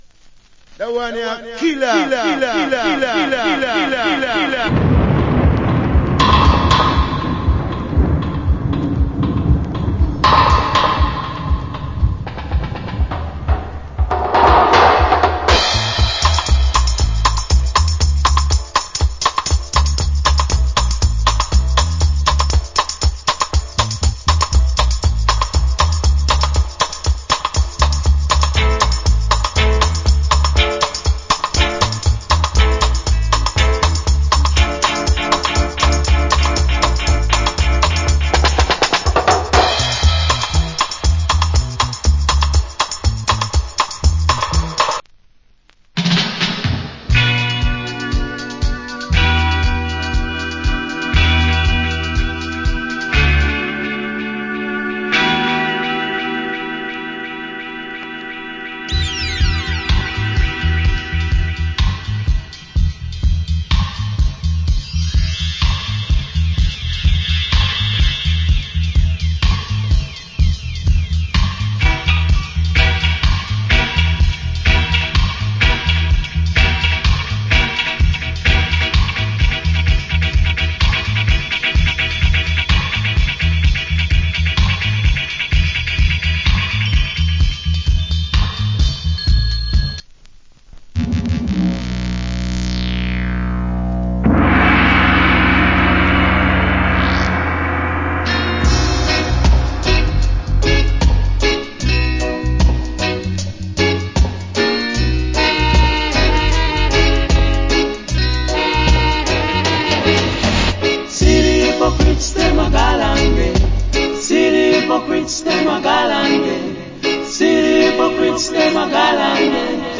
Great 70's Dub.